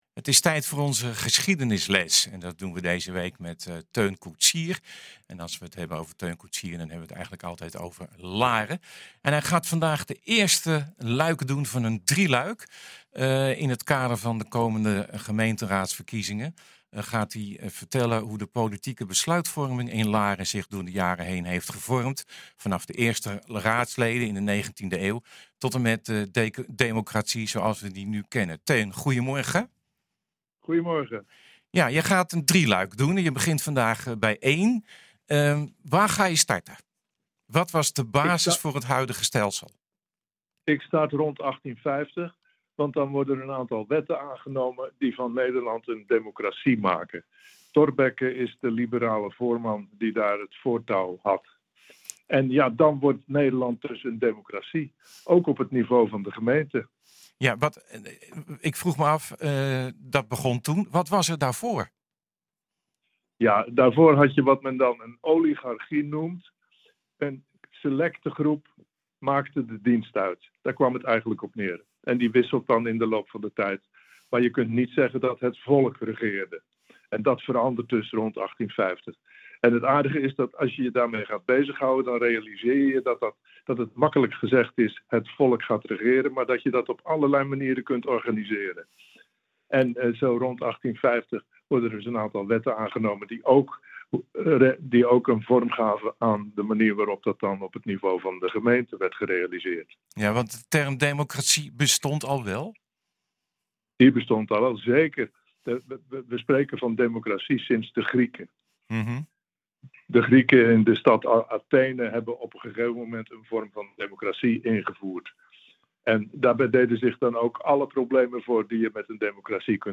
Onze gast van vandaag is geen onbekende in de studio.